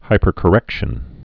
(hīpər-kə-rĕkshən)